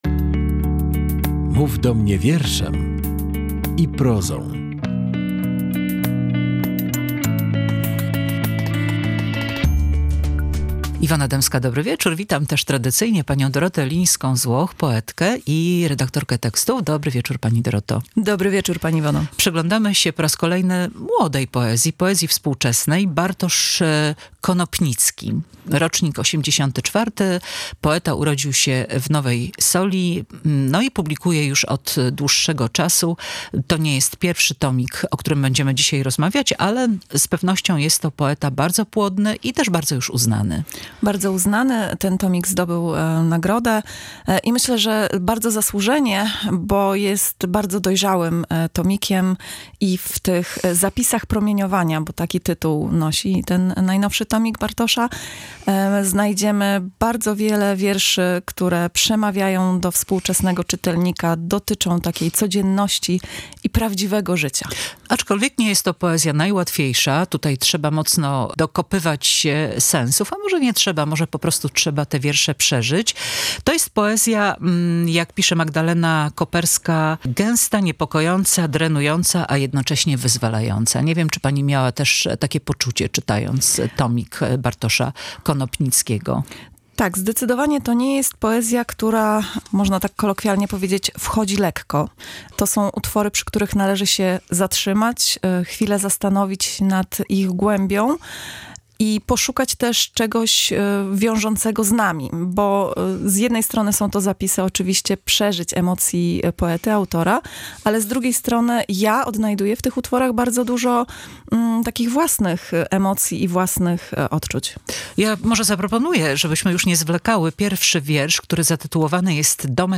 Rozmowa wokół poezji Bartosza Konopnickiego